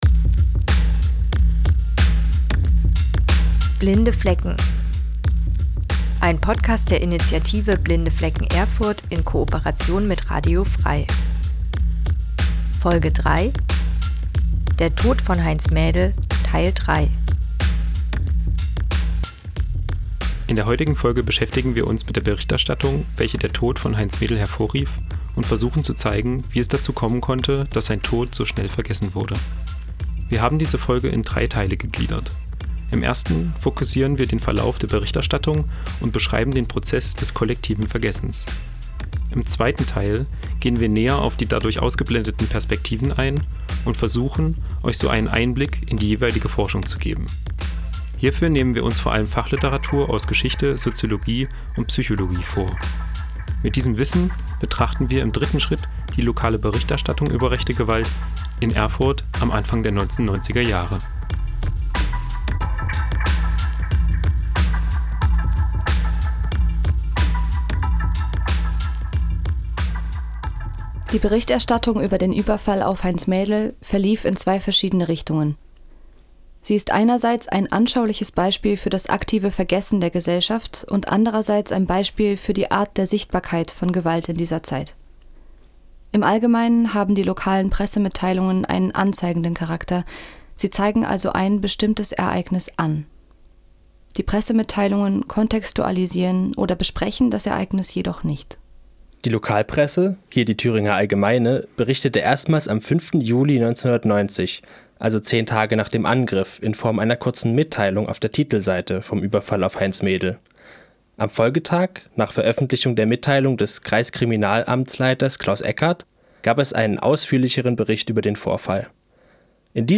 Text, Stimme, Musik: Blinde Flecken